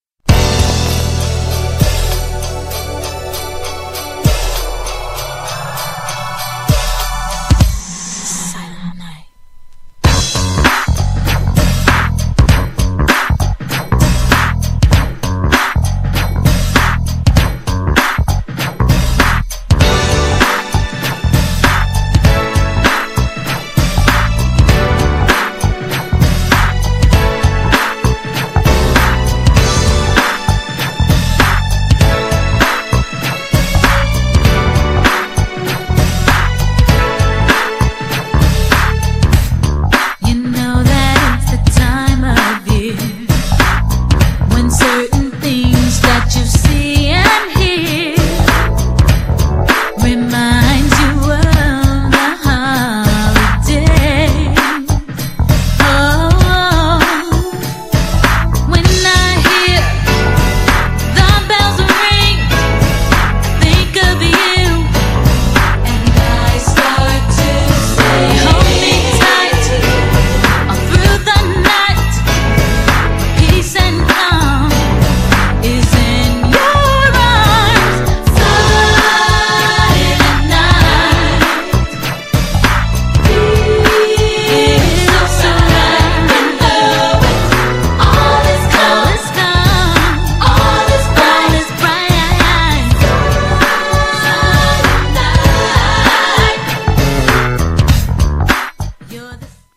クリスマス曲ですが、しっとりスローではなくそこそこに派手でアッパーな跳ねたビートのR&B!!
GENRE R&B
BPM 106〜110BPM
女性コーラスR&B